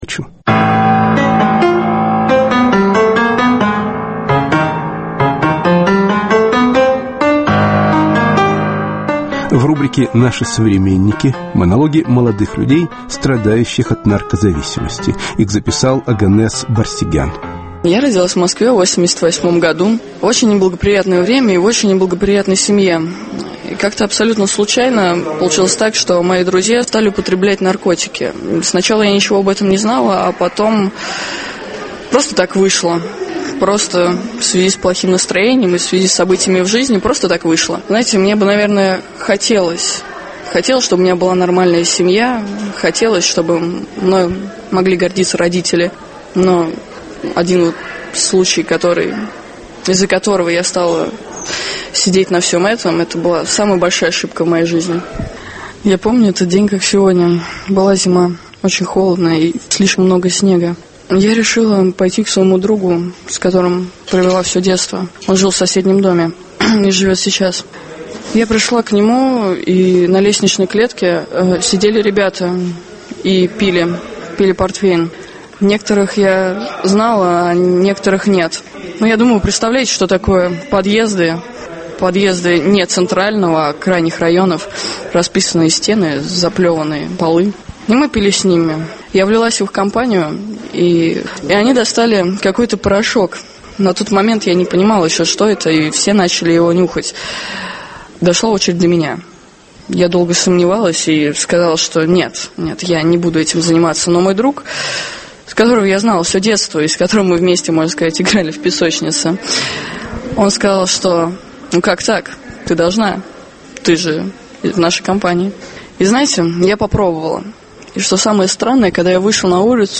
Монологи молодых людей, страдающих от наркозависимости.